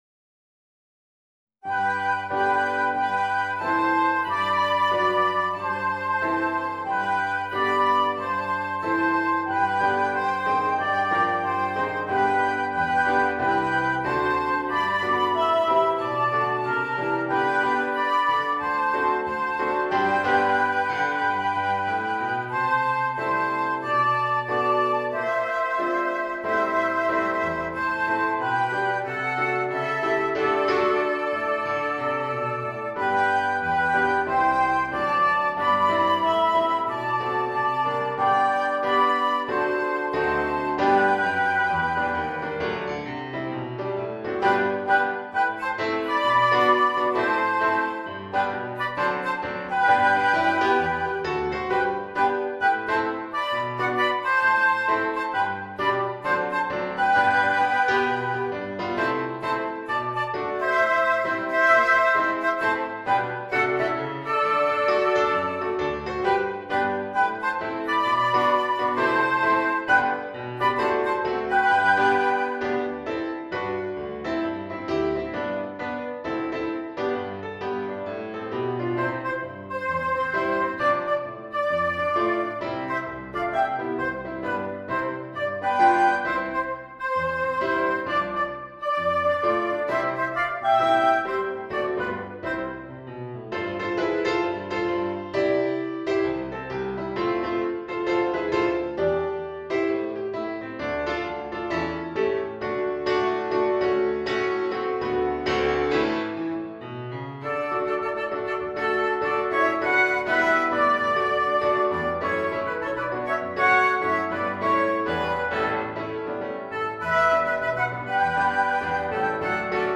木管二重奏+ピアノ